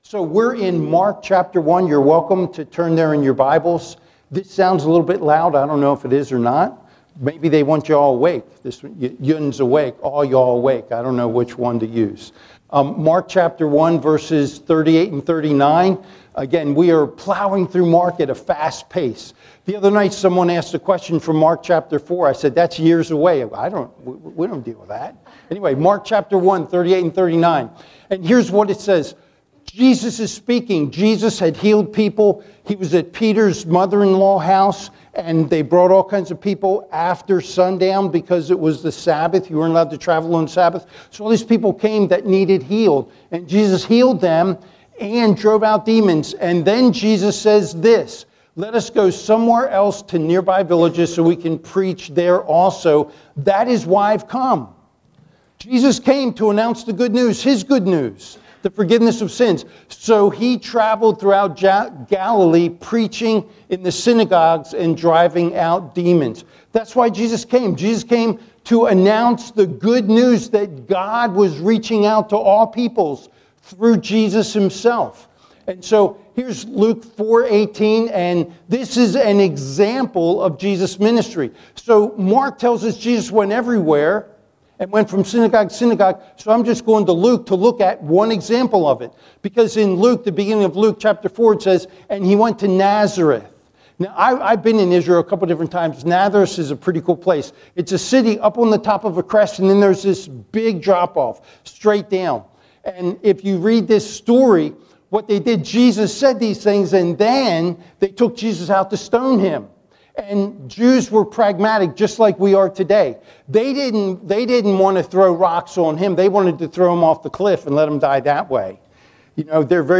Sermons | Buckhannon Alliance Church